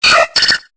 Cri de Togepi dans Pokémon Épée et Bouclier.